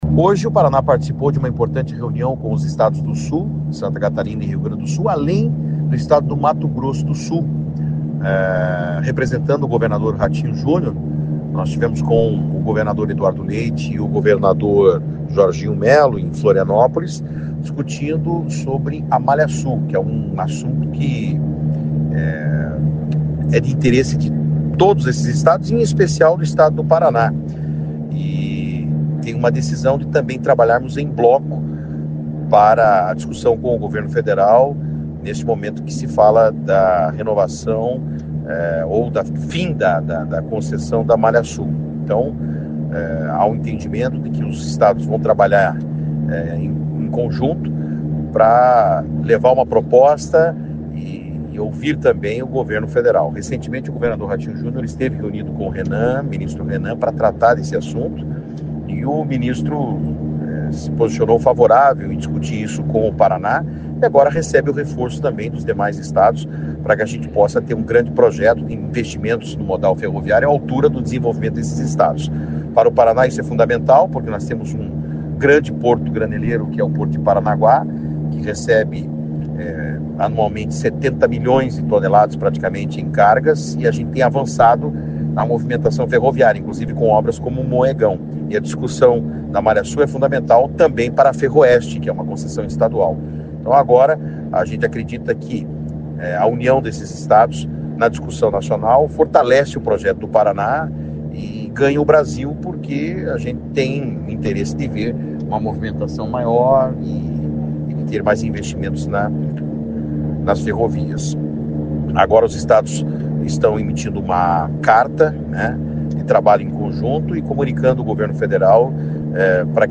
Sonora do secretário Estadual de Infraestrutura e Logística, Sandro Alex, sobre o trabalho conjunto dos estados do Sul com a união sobre ferrovias